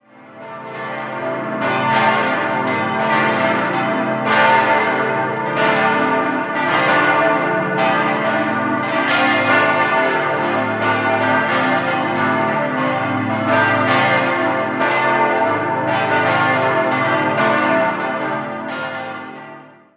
Im Jahr 1924 wurde schließlich die sehr schlicht gehaltene Franziskuskirche, die erste katholische Kirche des Orts, erbaut. 5-stimmiges ausgefülltes und erweitertes A-Moll-Geläute: a°-c'-d'-e'-g' Die Glocken wurden 1956 von der Gießerei Karl Czudnochowsky in Erding gegossen.